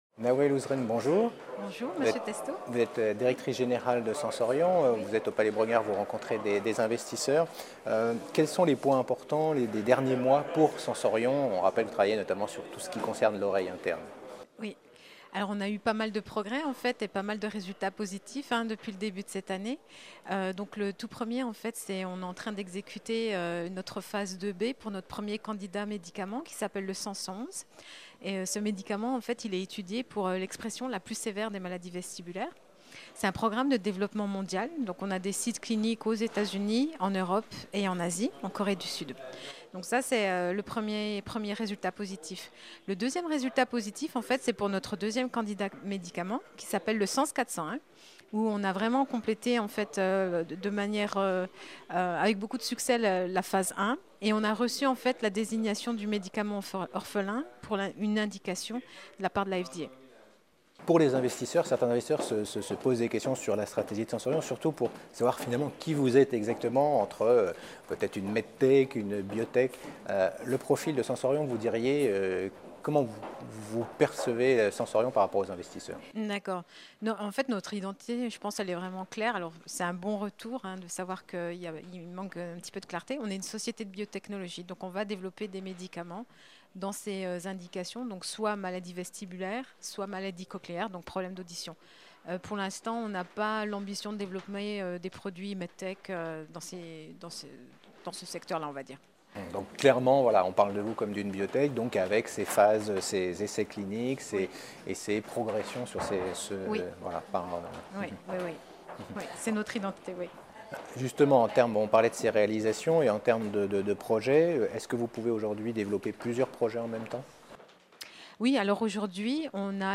Lors de l’European Large & Midcap Event 2017 organisé par CF&B Communication à Paris, la Web TV partenaire a rencontré de nombreux dirigeants.